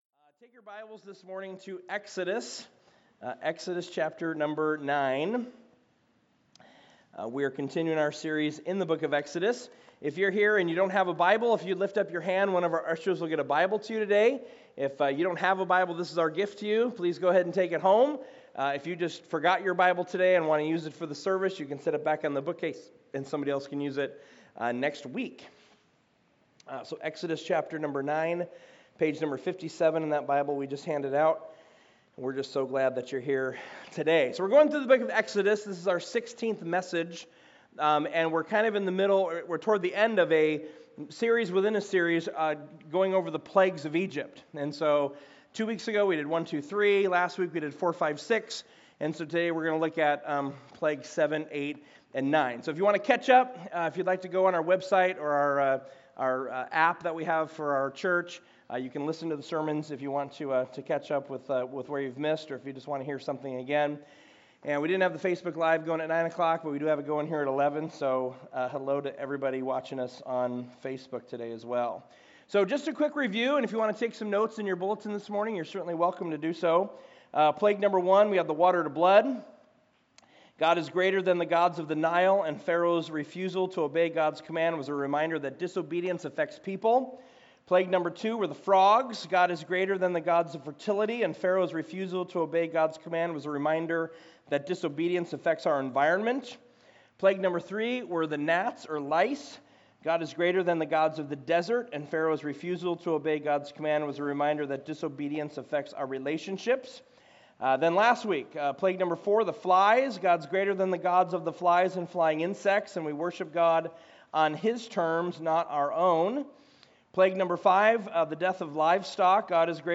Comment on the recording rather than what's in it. Service Type: Weekend Services